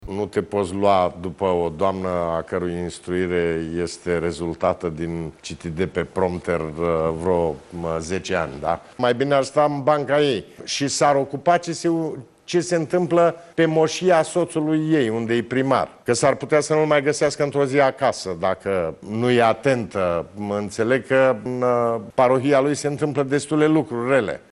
Replica lui Traian Băsescu a venit într-o emisiune TV.